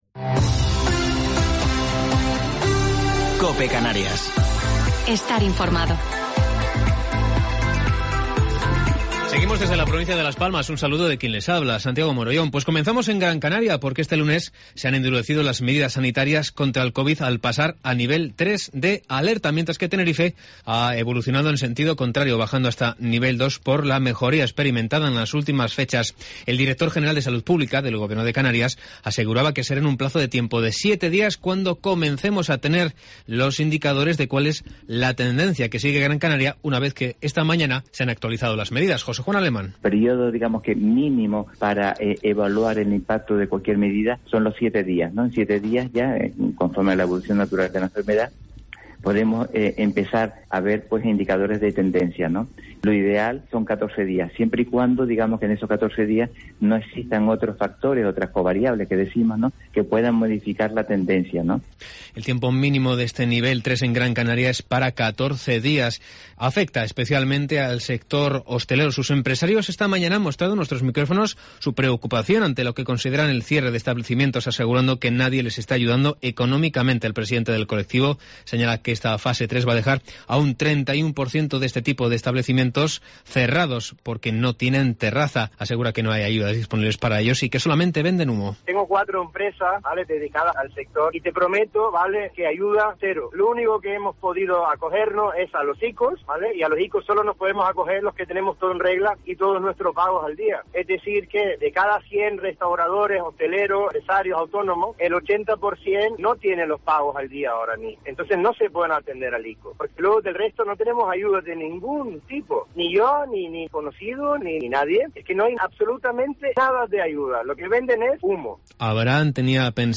Informativo local 18 de Enero del 2021